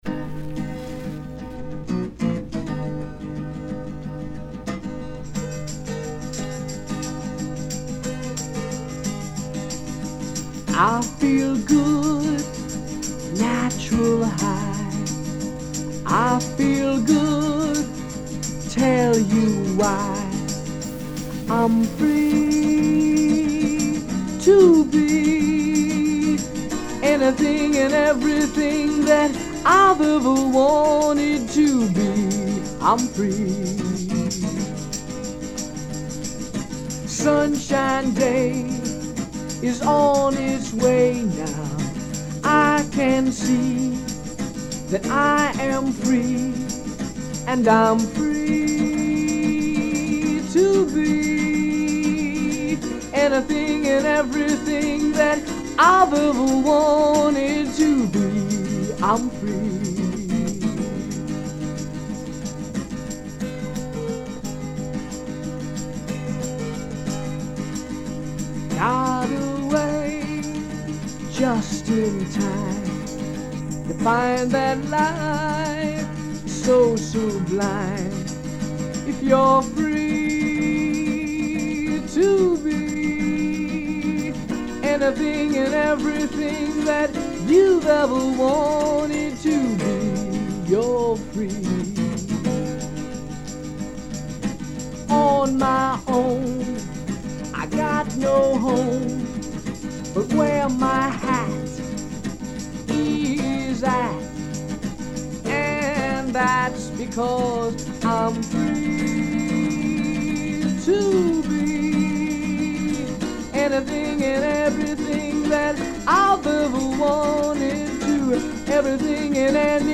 To practice singing and for writing songs, I played the guitar.
We recorded it on his reel-to-reel tape recorder; very high tech in 1967!